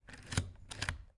描述：在Zoom H4n和外部DPA 4006麦克风上使用立体声中端技术录制的按下和重新传输自动上墨印章的声音